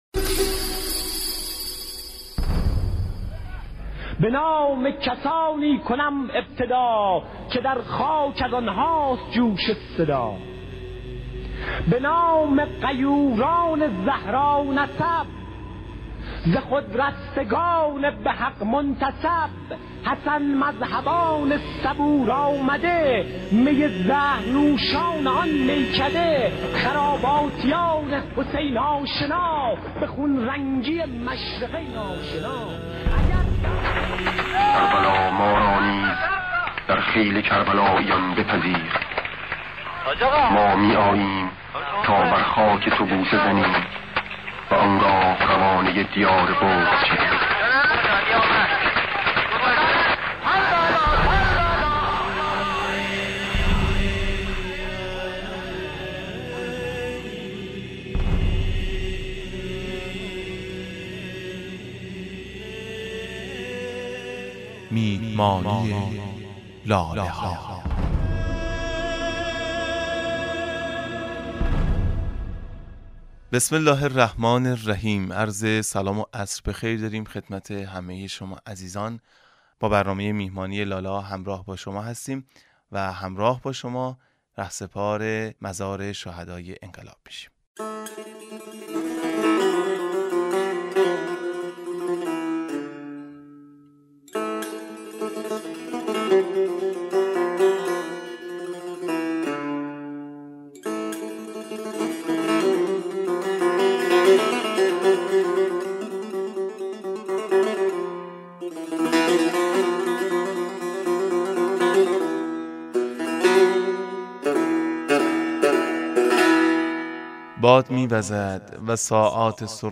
نوید شاهد - برنامه رادیویی